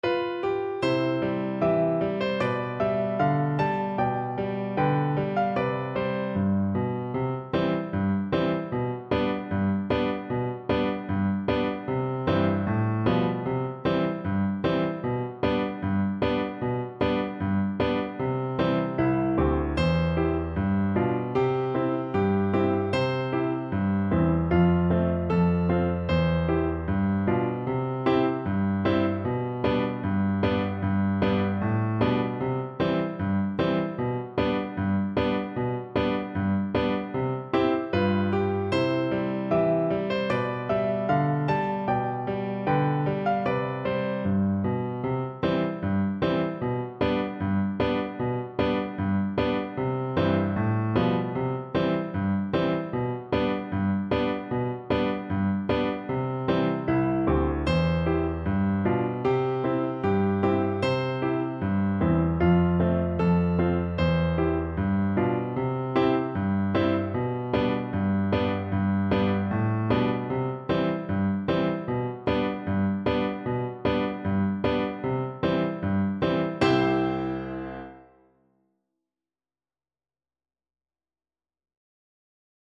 2/4 (View more 2/4 Music)
Steadily =c.76